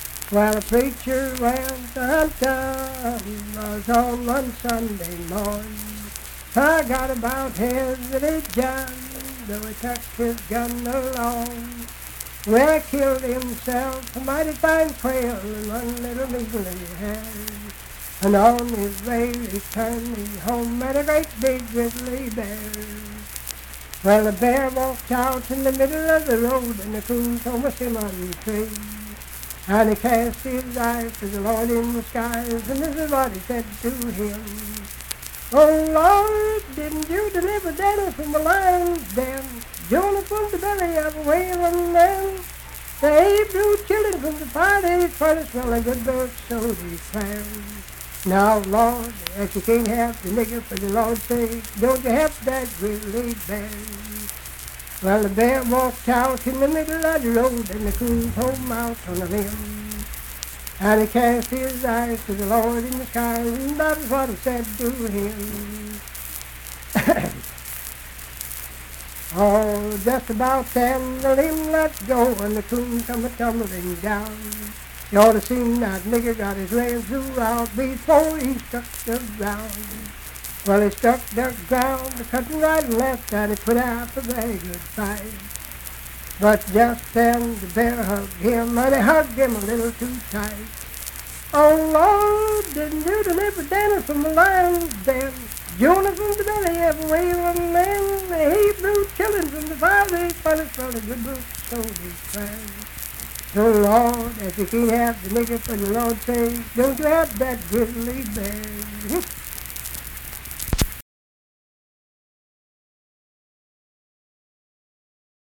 Unaccompanied vocal music
Performed in Ivydale, Clay County, WV.
Humor and Nonsense, Minstrel, Blackface, and African-American Songs
Voice (sung)